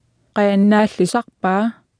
Martha tassaavoq kalaallisut qarasaasiakkut atuffassissut.